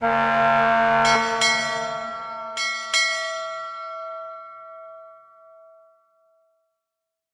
Amb_dlc04boatarrival_bell.ogg